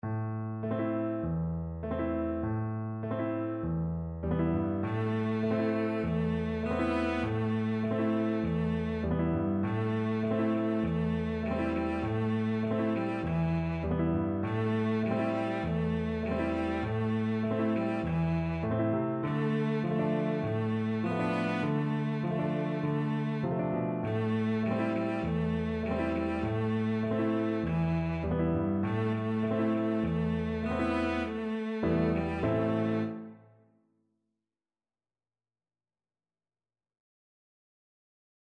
Cello version
Moderato